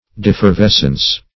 Defervescence \De`fer*ves"cence\, Defervescency